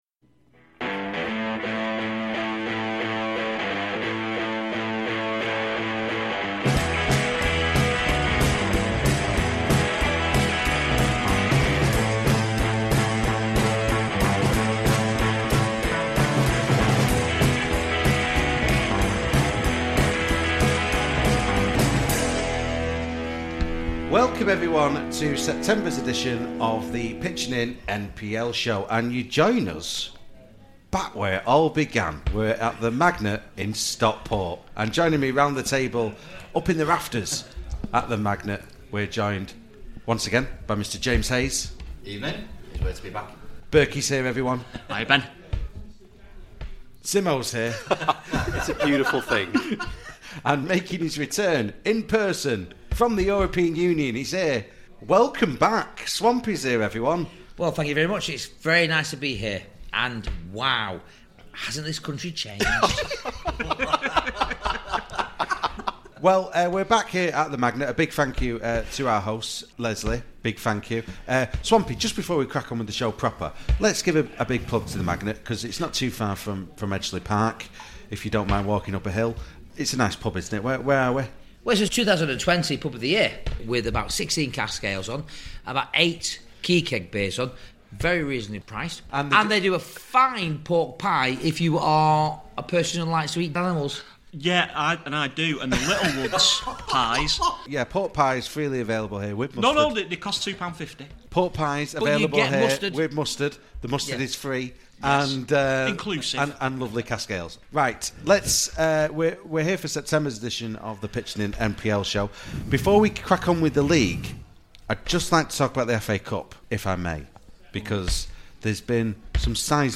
After months and months of Zoom meetings, The NPL Show is back in person, as we review the past month of Northern Premier League action.
The opinions expressed within this programme are solely those of the contributors involved, and do not express the views or opinions of the Northern Premier League This programme was recorded at a The Magnet on Wednesday 6th October 2021.